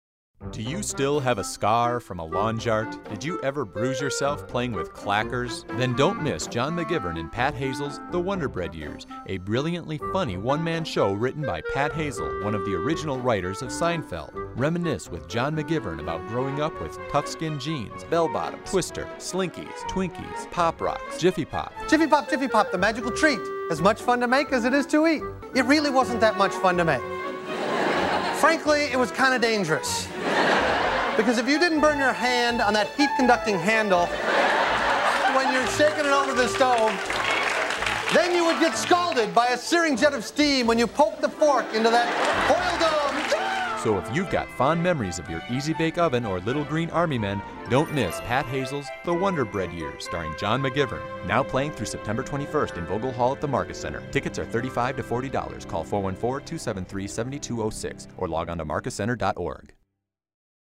Wonderbread Years Radio Commercial